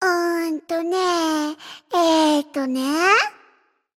[閒聊] 主戰隱藏語音